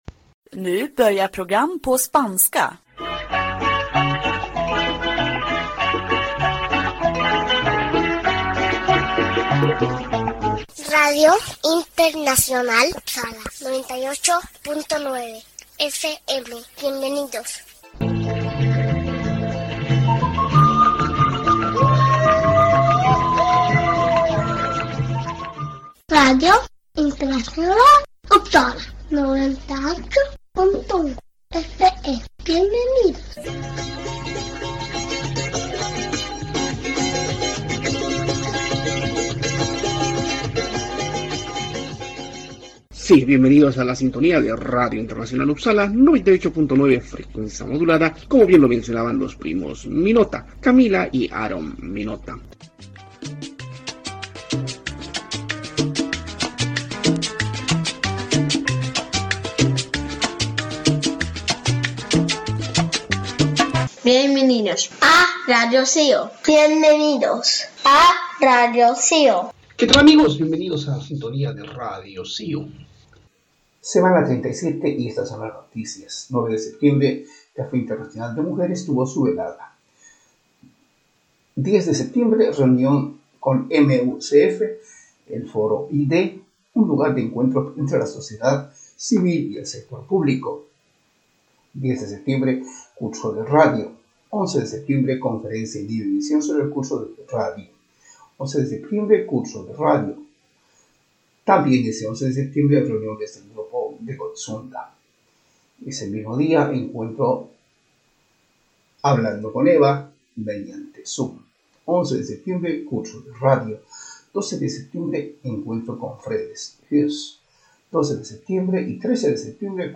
Sí, radio de cercanías en Uppsala se emite domingo a domingo a horas 18:30. La asociación de amistad Suecia-Latinoamérica SANKHAYU está detrás de todo este trabajo informativo,que fundamentalmente enfoca a noticias que tienen que ver con el acontecer de los inmigrantes en Uppsala.